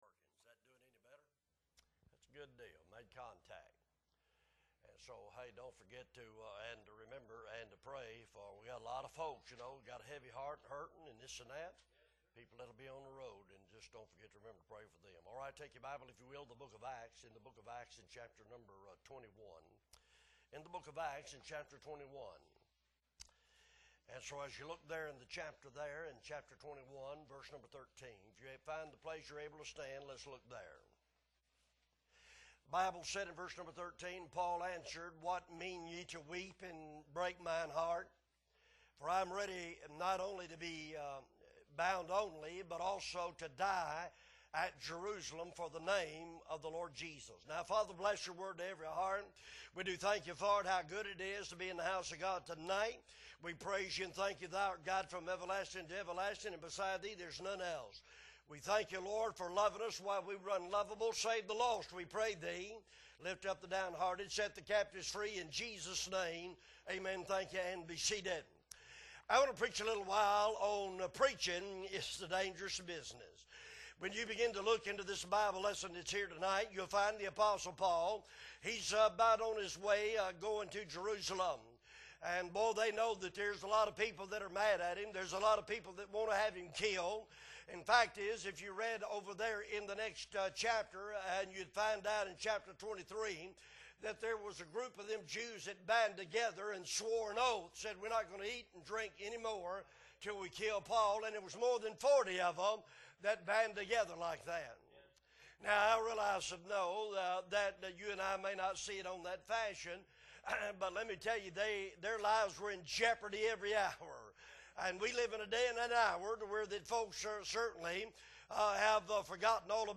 October 8, 2023 Sunday Evening Service - Appleby Baptist Church